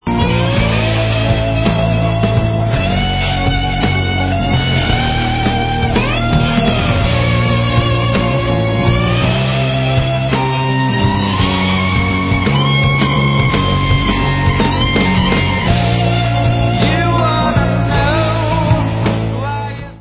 sledovat novinky v oddělení Hard Rock